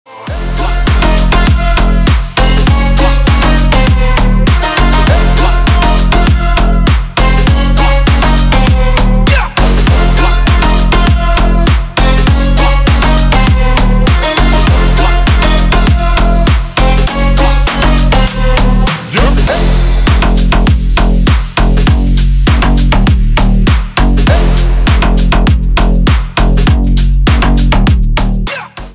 Клубные треки в рингтонах
• Качество: Хорошее